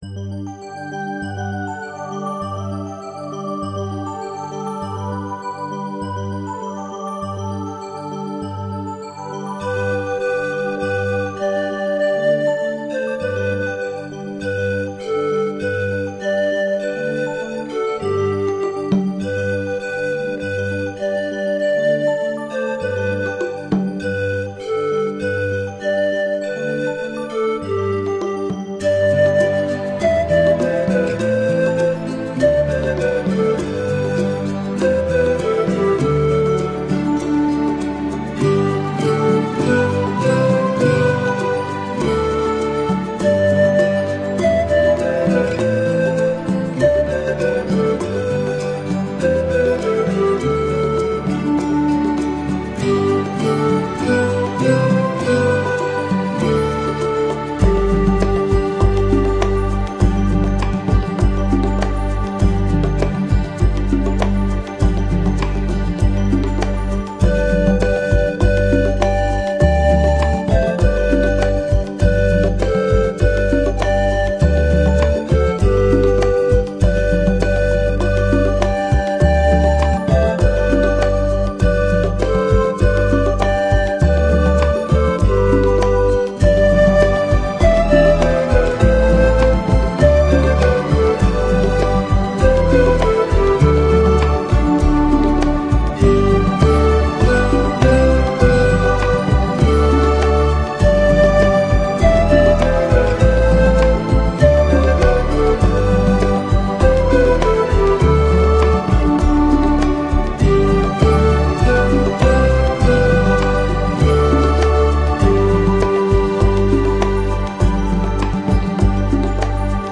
Жанр: Pan Flute, Guitar, Instrumental